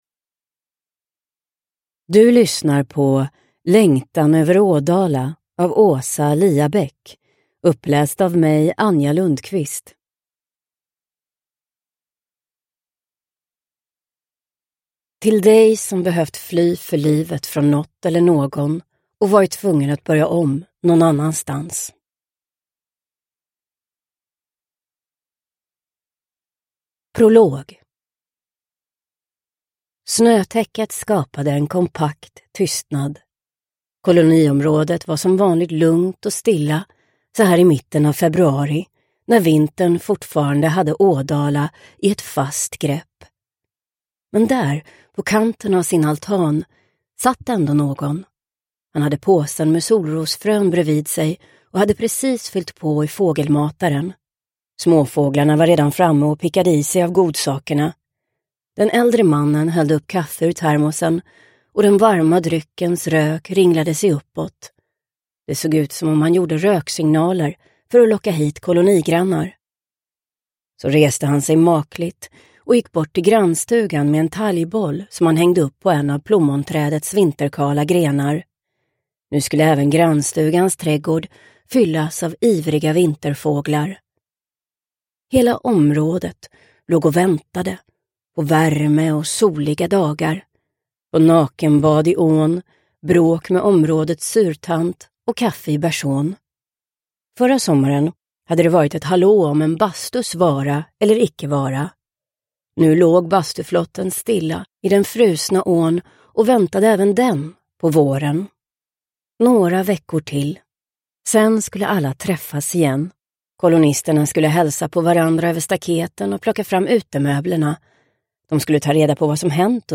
Längtan över Ådala – Ljudbok – Laddas ner